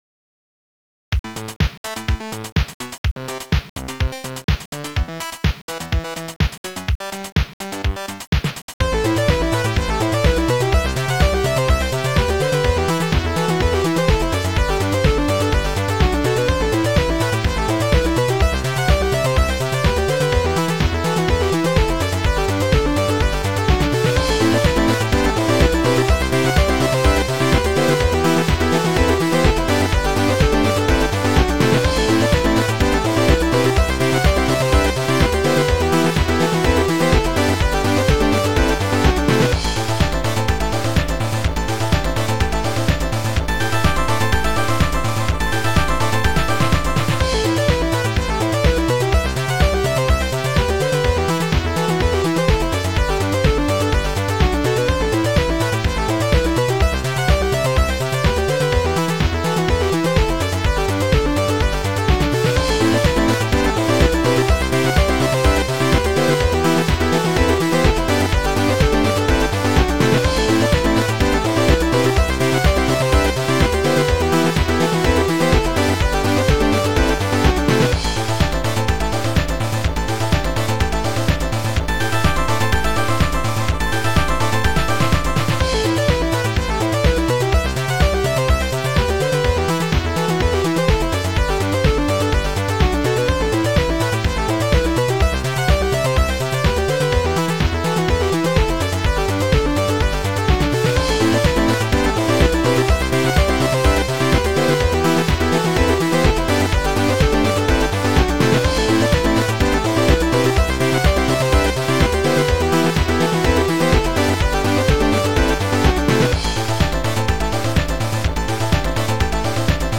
Keygen Music